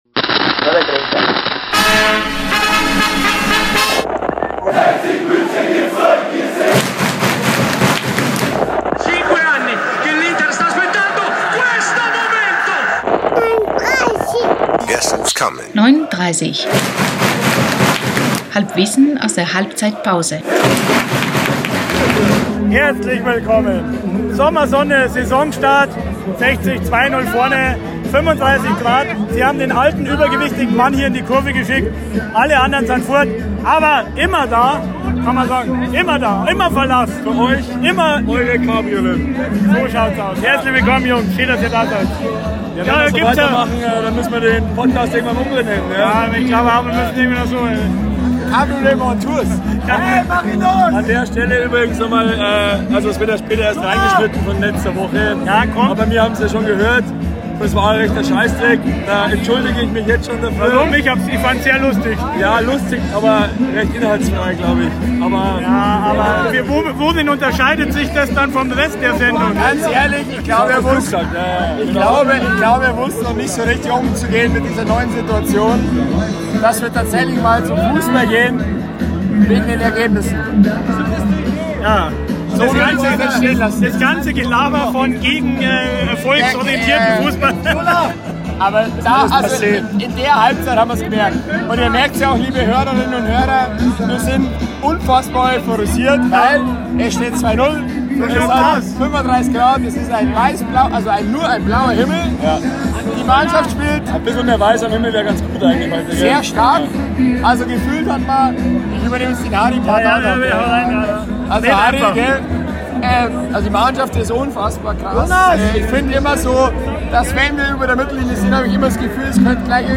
Auch die Tonqualität entspricht nicht dem, was ihr neun30-Hörer von einem HiFi-Podcast wie dem unseren gewohnt seid (warum, siehe Folge 179).